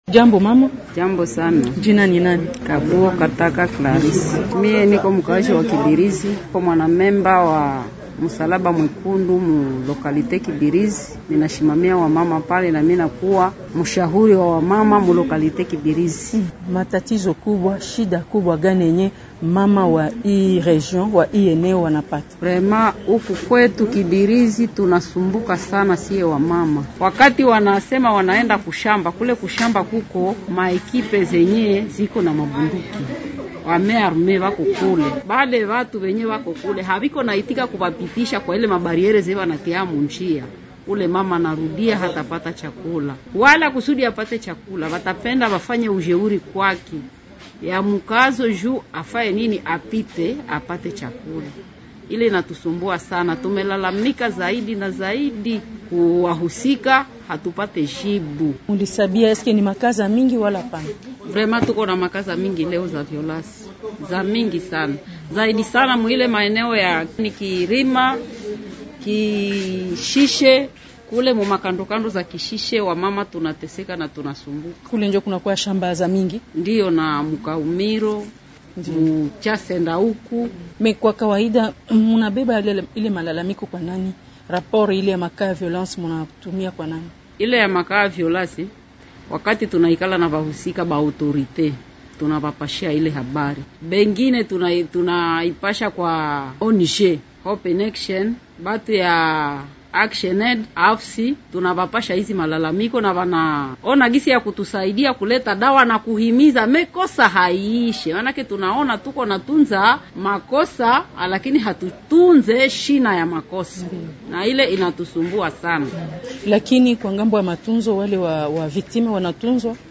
Anaeleza hali ilivyo katika mahojiano haya